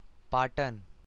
pronunciation) is the administrative seat of Patan district in the Indian state of Gujarat and is an administered municipality.